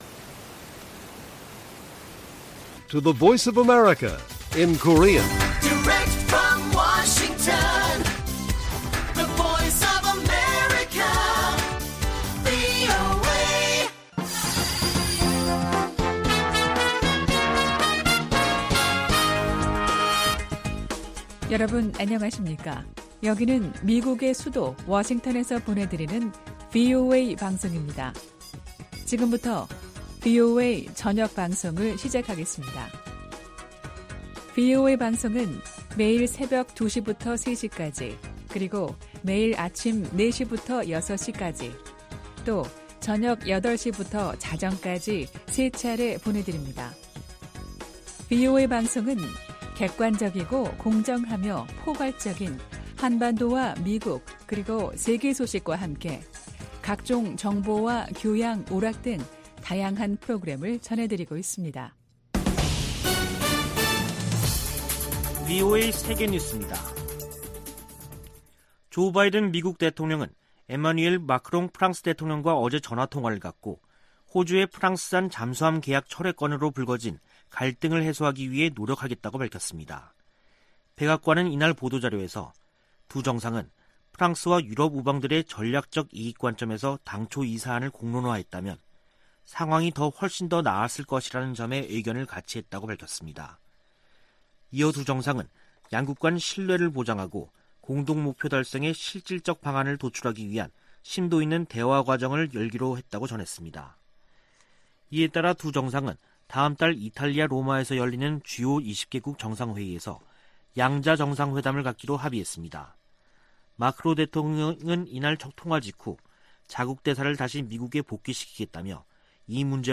VOA 한국어 간판 뉴스 프로그램 '뉴스 투데이', 2021년 9월 23일 1부 방송입니다. 미국은 한국전쟁 종전선언 논의에 열려 있다고 미 국방부 대변인이 밝혔습니다. 미 상원 군사위원회가 2022 회계연도 국방수권법안 전문을 공개했습니다. '북한자유연합(NKFC)'이 24일 탈북 난민 구출의 날을 앞두고 시진핑 중국 국가주석에게 보내는 공개서한을 발표했습니다.